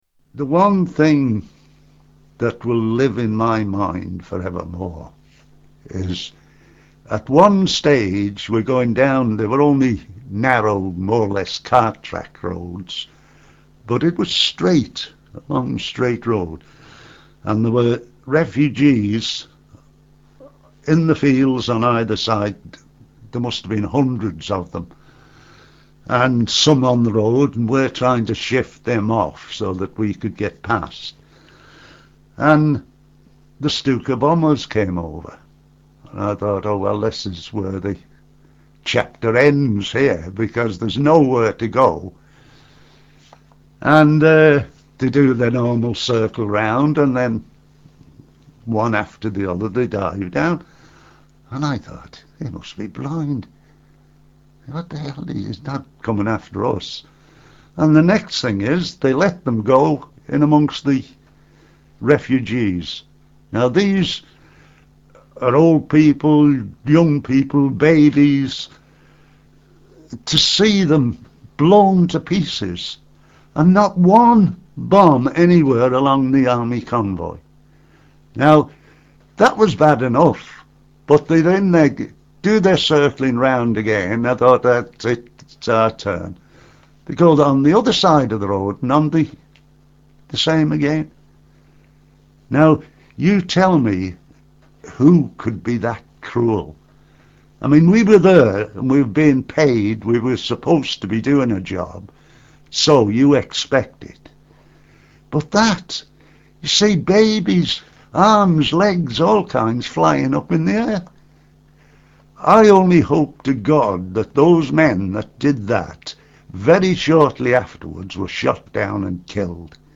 Tags: The Dunkirk Survivors Dunkirk Survivors Dunkirk Dunkirk Survivor interviews World war 2